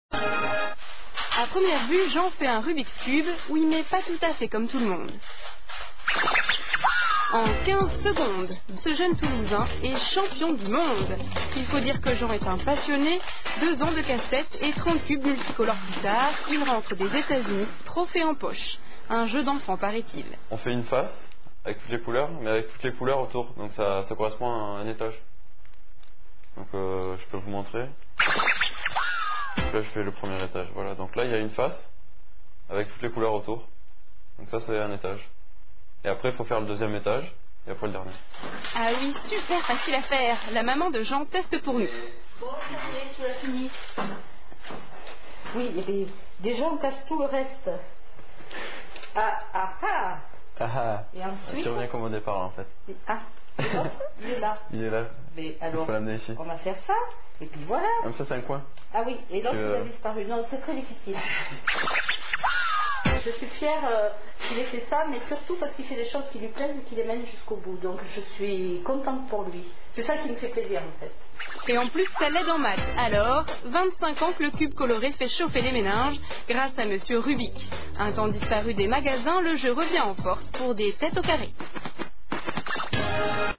Bande-son du reportage (mp3 - 1min.23 sec. - 164 Ko)